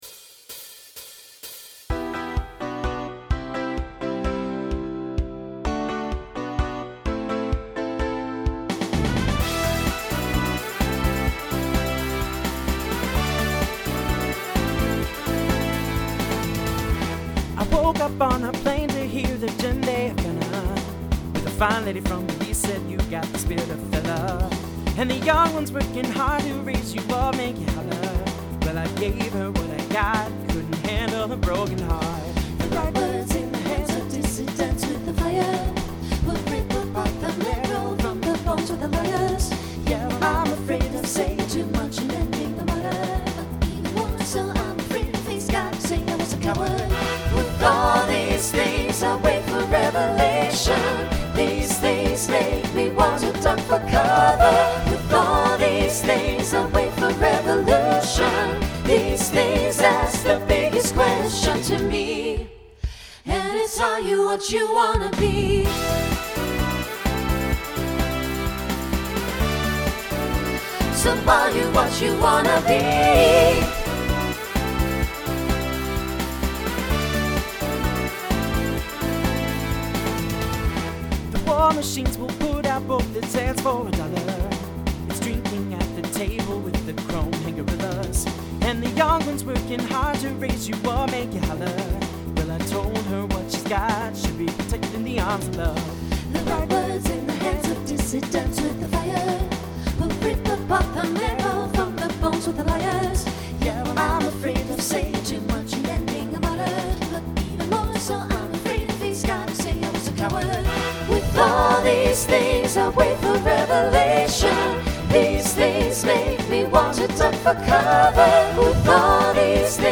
SATB Instrumental combo
Rock
Mid-tempo